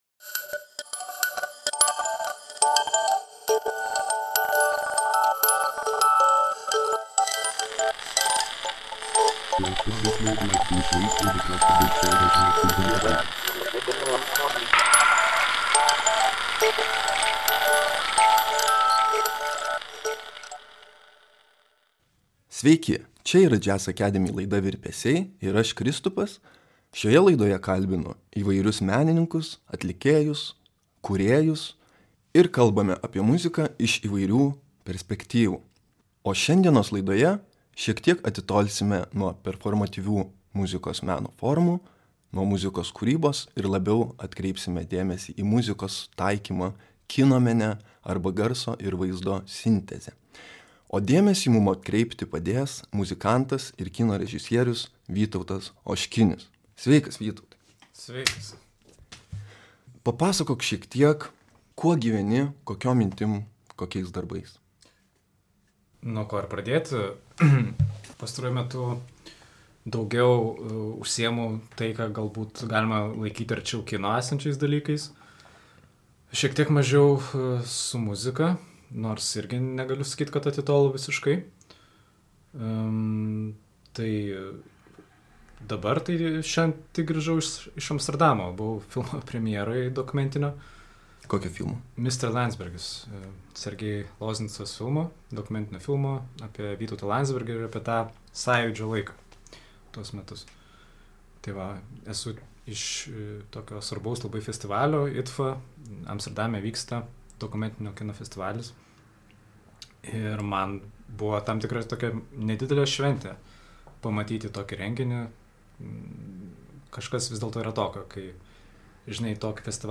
Pokalbis su muzikantu ir kino režisieriumi
Podcast’e panaudoti tik žemiau nurodytų autorių muzikos fragmentai.